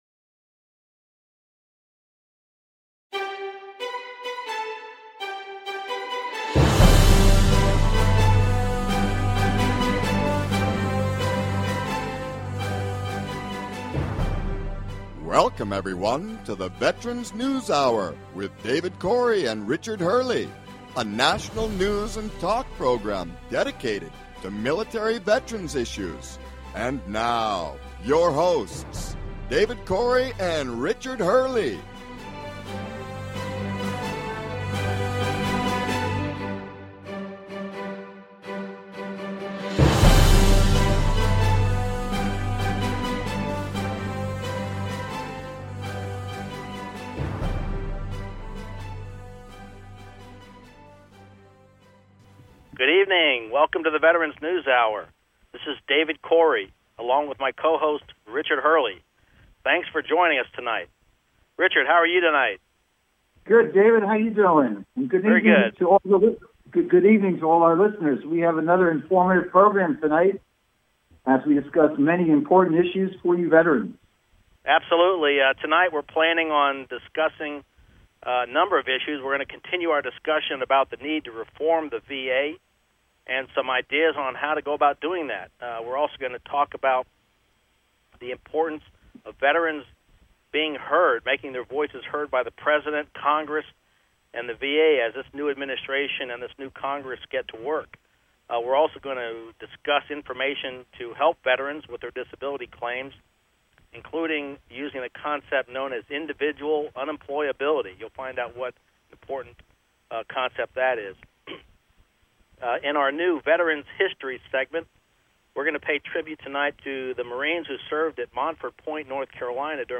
News and talk show about military veterans issues, including VA benefits and all related topics.